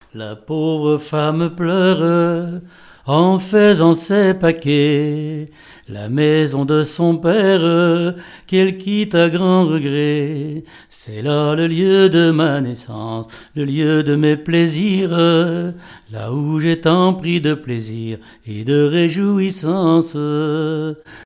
circonstance : fiançaille, noce
Genre strophique
répertoire de chansons, et d'airs à danser
Pièce musicale inédite